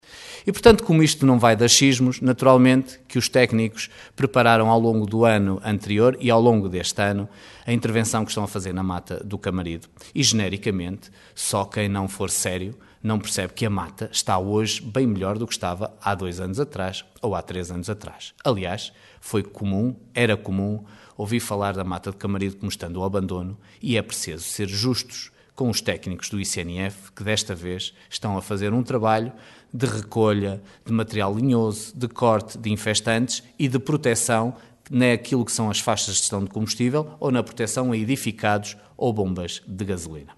Opinião bem distinta manifestou o presidente da Câmara de Caminha, Miguel Alves que diz confiar no ICNF e nos seus profissionais, um organismo que considera ser bastante rigoroso.